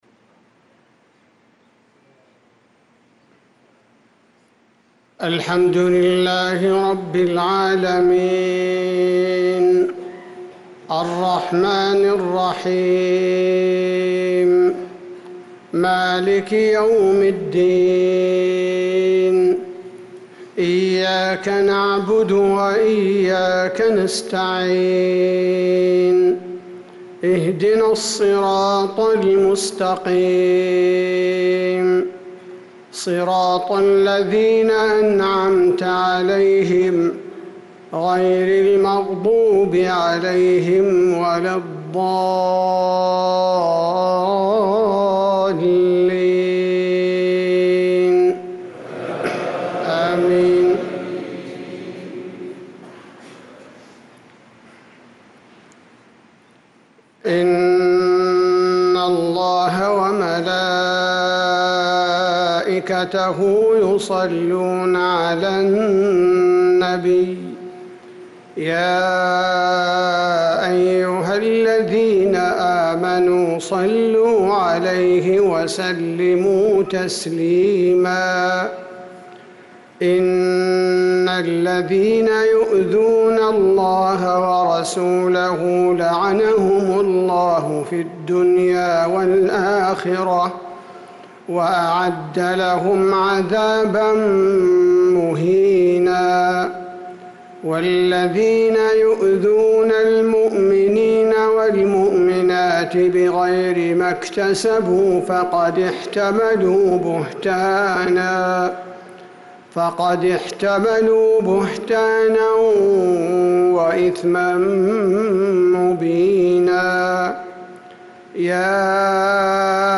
صلاة العشاء للقارئ عبدالباري الثبيتي 14 ذو الحجة 1445 هـ
تِلَاوَات الْحَرَمَيْن .